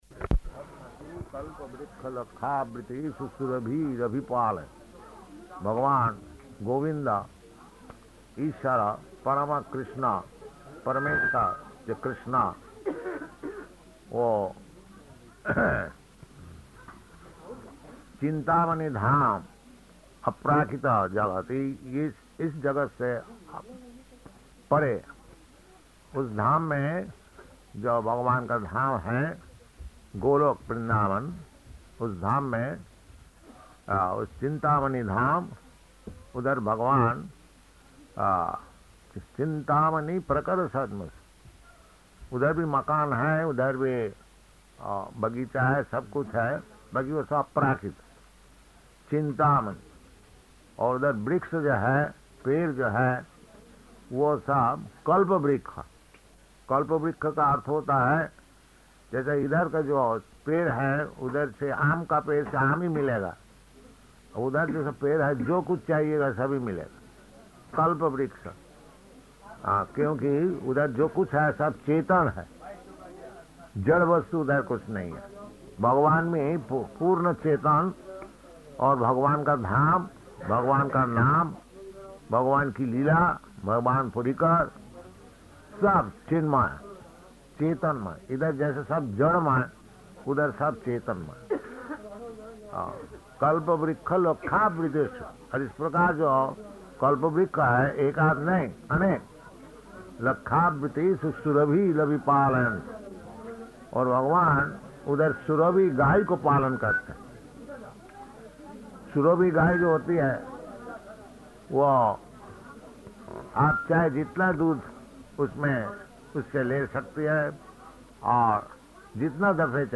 Lecture in Hindi [Partially Recorded]
Location: Allahabad
Śrī Caitanya-caritāmṛta Lecture in HindiPaṇḍāl Lecture [Partially Recorded]